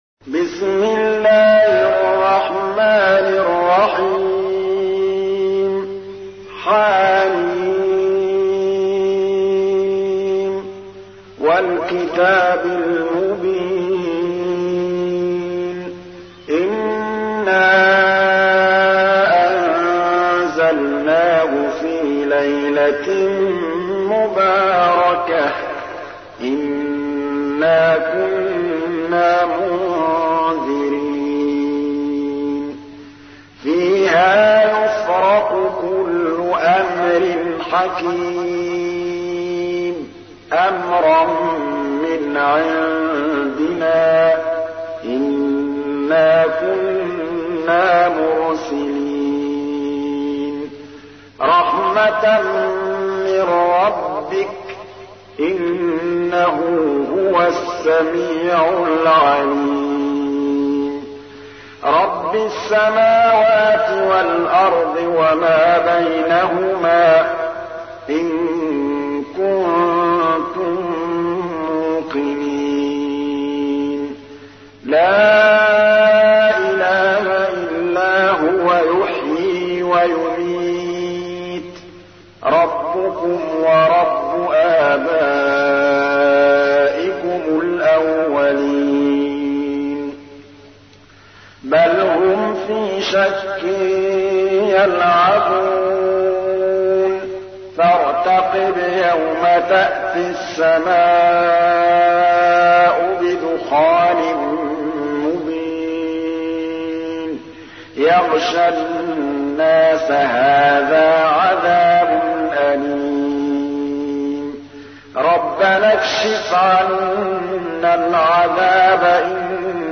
تحميل : 44. سورة الدخان / القارئ محمود الطبلاوي / القرآن الكريم / موقع يا حسين